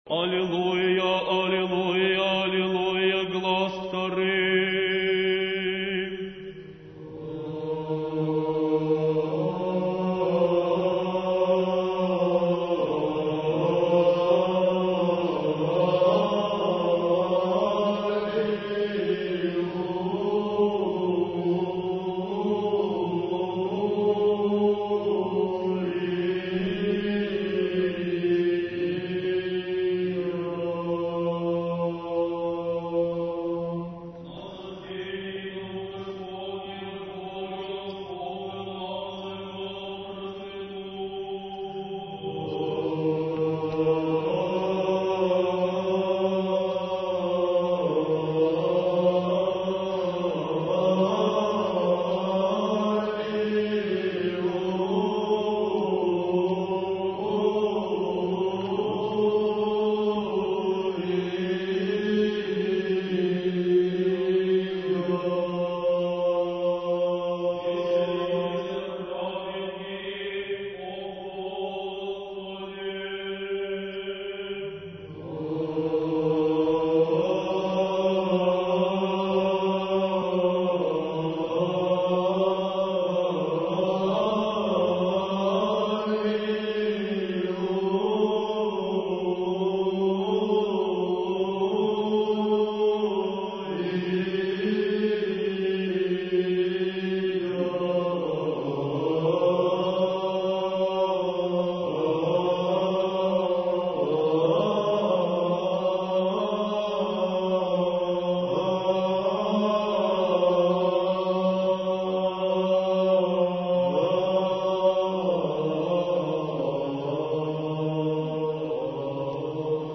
Духовная музыка
Аллилуия. Чтение Евангелия